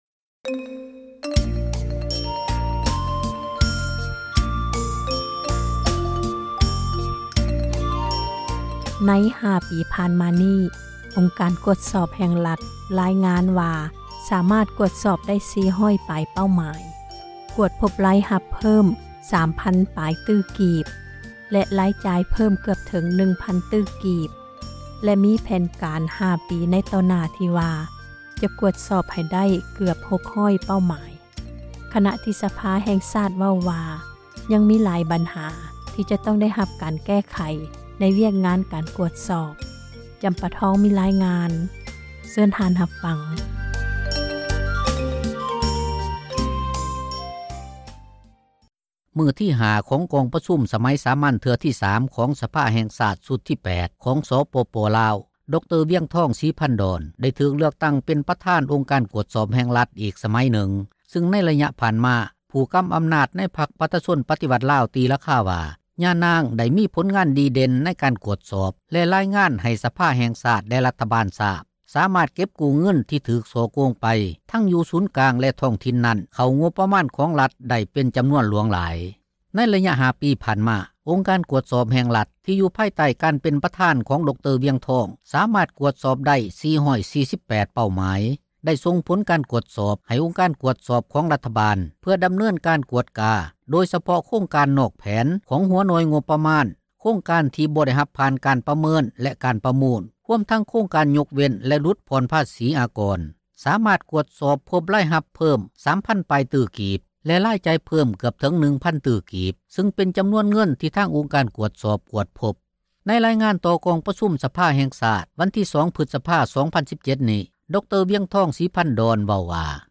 ໃນຣາຍງານຕໍ່ກອງປະຊຸມສະພາແຫ່ງຊາດ ວັນທີ 2 ພຶສພາ 2017 ນີ້ ດຣ.ວຽງທອງ ສີພັນດອນ ເວົ້າວ່າໃນລະຍະ 5 ປີ ທີ່ຜ່ານມາ ອົງການ ກວດສອບ ແຫ່ງຣັຖ ສາມາດ ກວດສອບໄດ້ ທັງໝົດ 448 ເປົ້າໝາຍ.